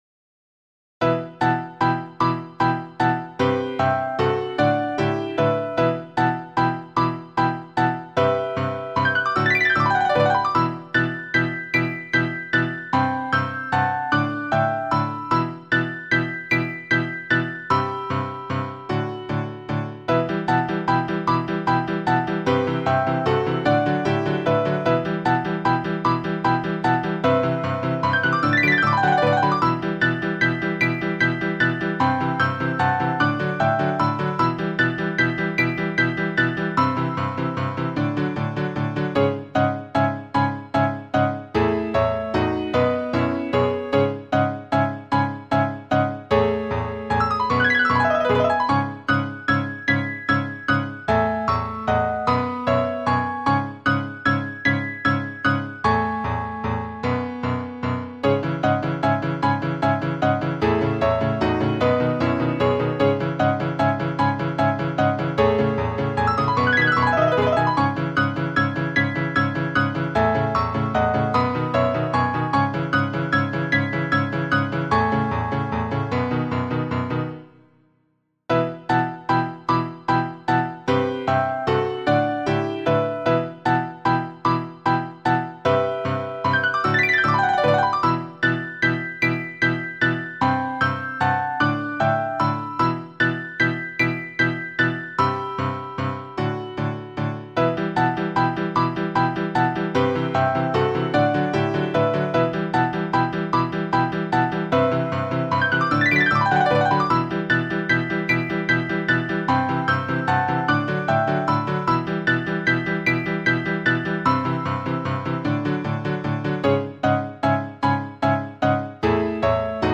耳コピ作品